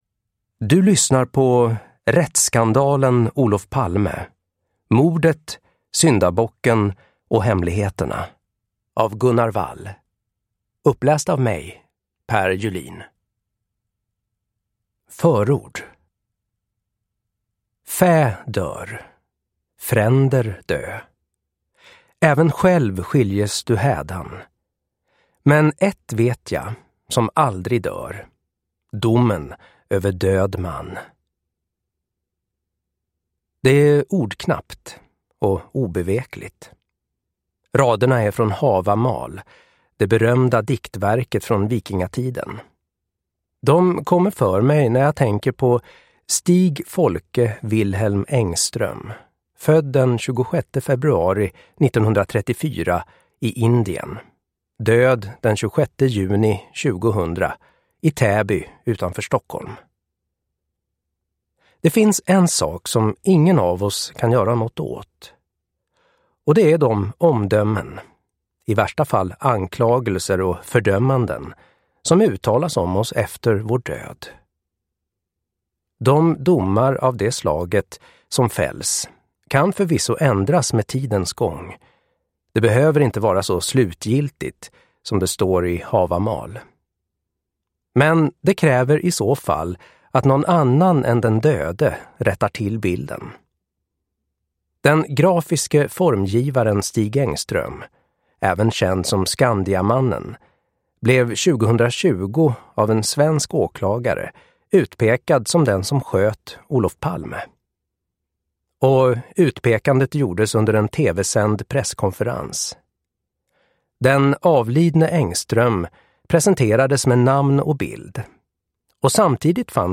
Rättsskandalen Olof Palme : mordet, syndabocken och hemligheterna (ljudbok) av Gunnar Wall